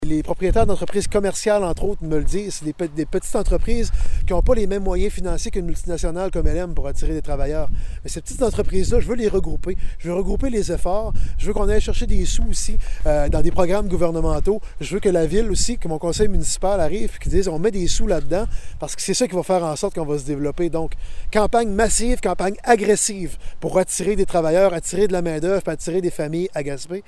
Sur ce dernier point, Daniel Côté en a clarifié l’objectif, aujourd’hui en conférence de presse, devant l’usine de LM Wind Power.